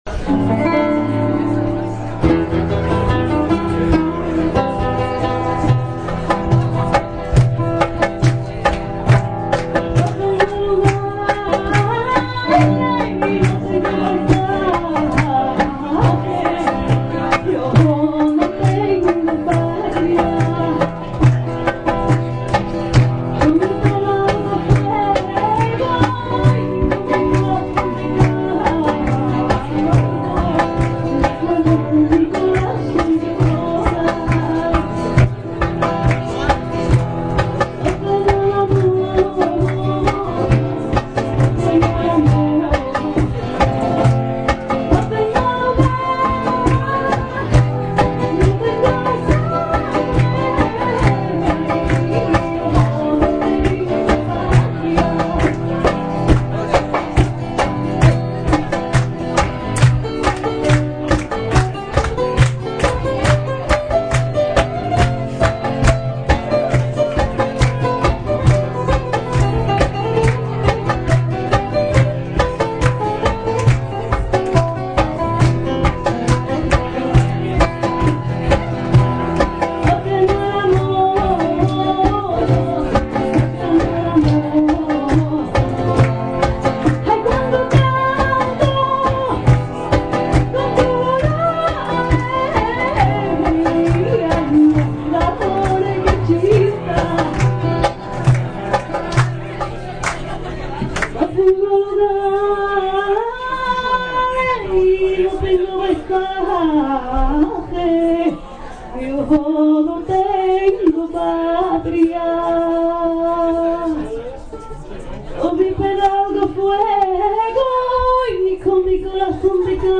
! Extraits du concert au format mp3 !